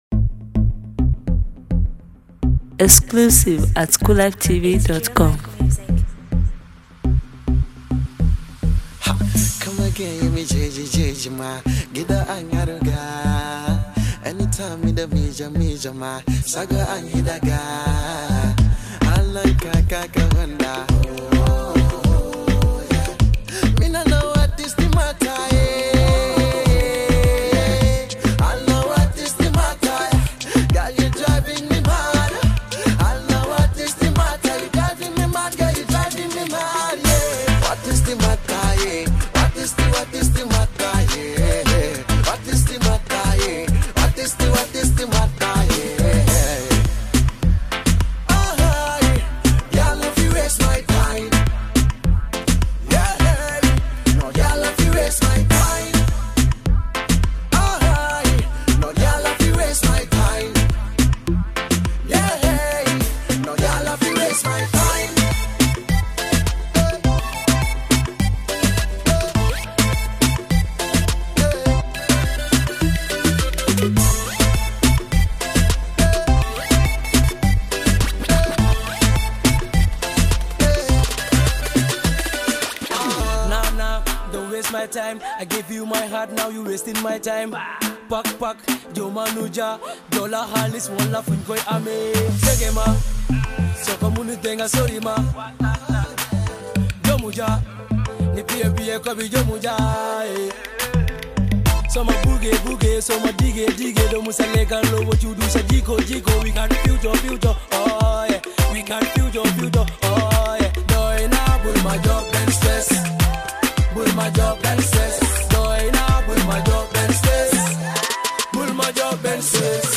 3 boy band sensations